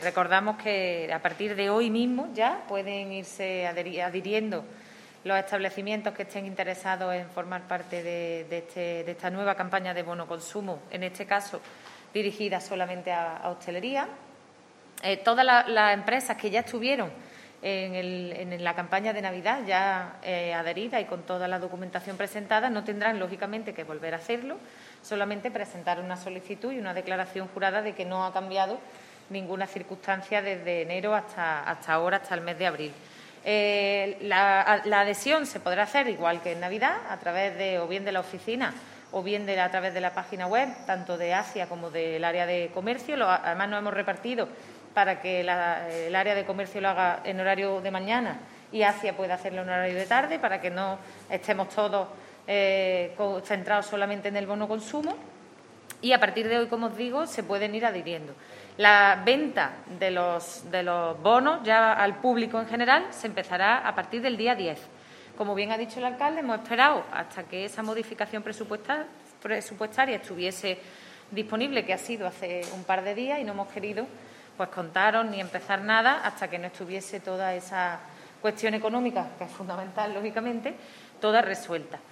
El alcalde de Antequera, Manolo Barón, ha anunciado hoy en rueda de prensa la puesta en marcha de una nueva campaña de BonoConsumo específica, en este caso, para la hostelería local.
Cortes de voz